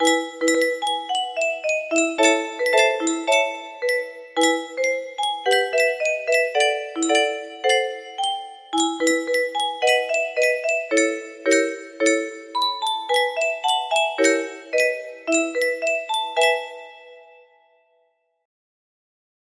BPM 110